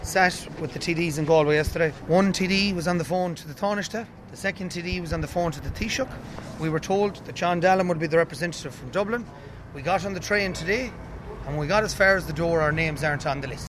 After discussions outside, protestors